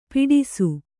♪ piḍisu